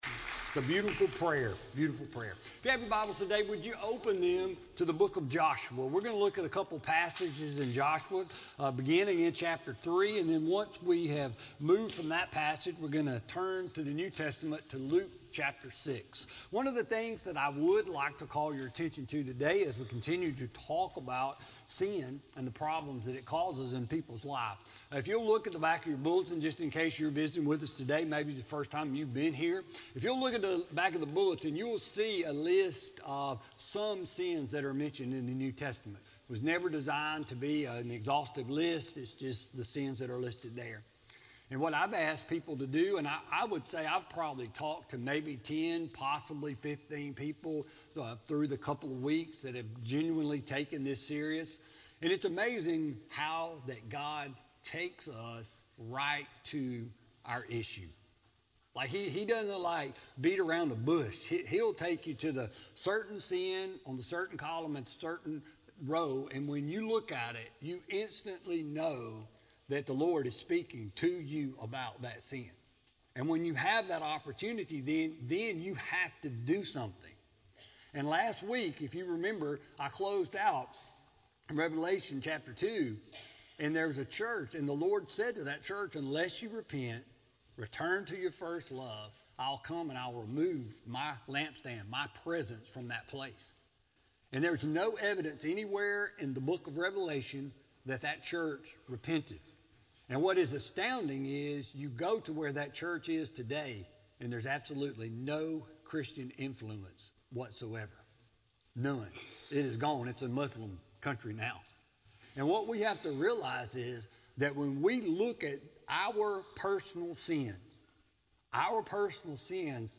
March 5, 2017 Sermon